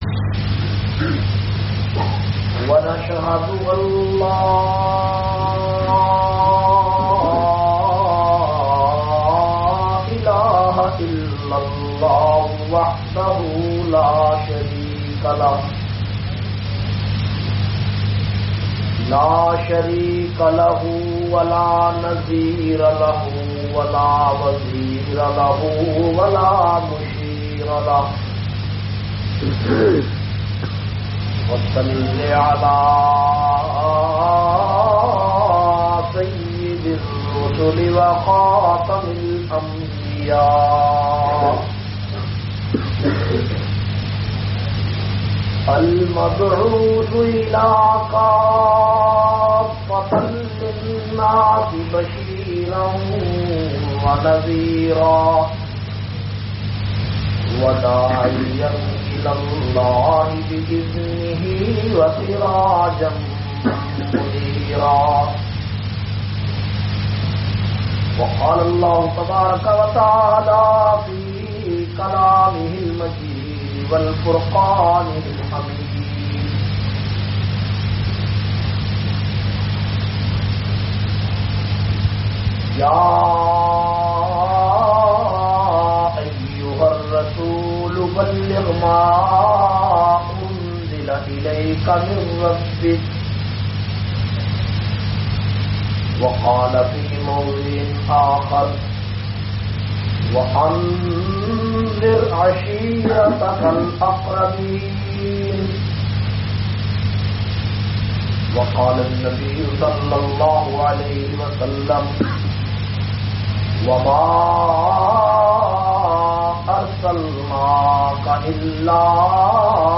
432- Seerat un Nabi Khawateen se khittab Madrasa Taleem ul Quran Edinburgh England.mp3